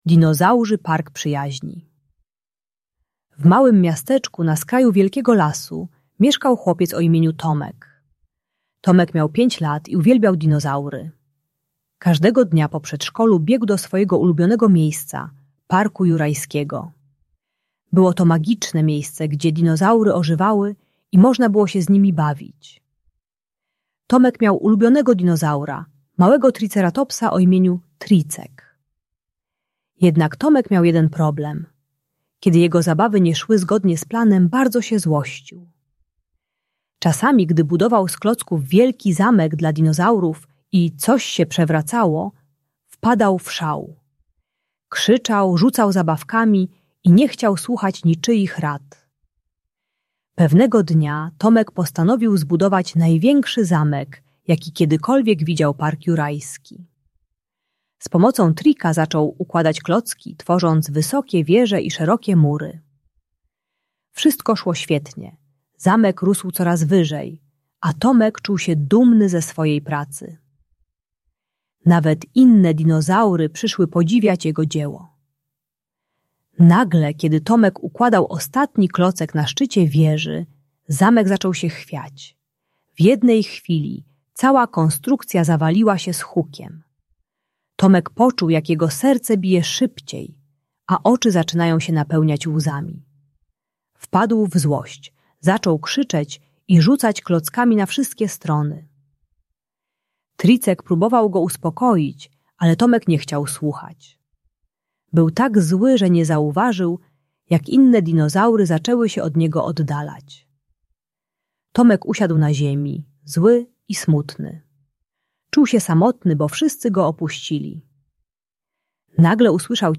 Dinozaurzy Park Przyjaźni - Bunt i wybuchy złości | Audiobajka